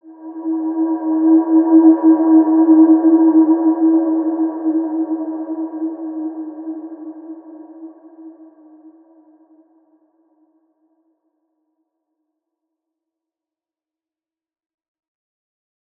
Large-Space-E4-mf.wav